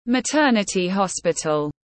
Bệnh viện phụ sản tiếng anh gọi là maternity hospital, phiên âm tiếng anh đọc là /məˈtɜː.nə.ti hɒs.pɪ.təl/.
Maternity-hospital.mp3